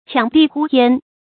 抢地呼天 qiāng dì hū tiān
抢地呼天发音